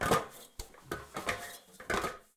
Aurora.3/sound/effects/ladder4.ogg
ladder4.ogg